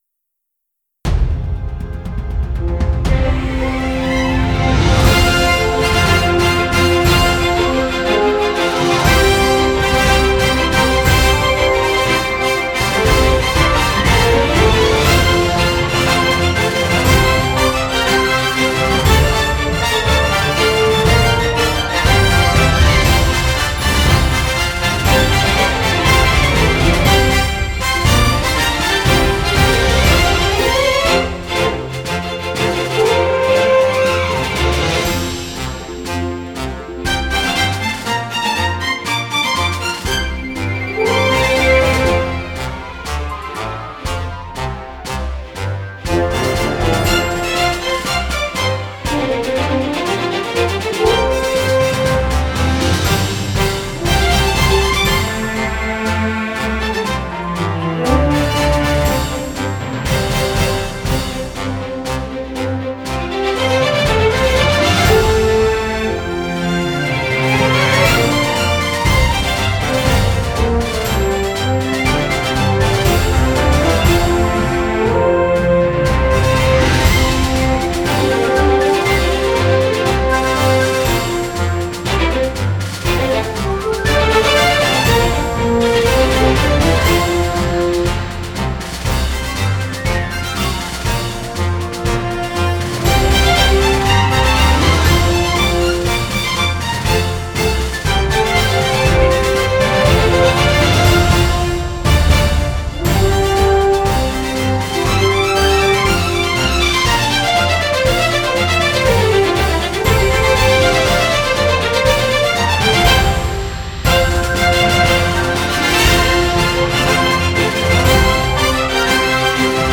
山东纵队进行曲（乐曲）.mp3